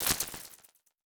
Futuristic Sounds (24).wav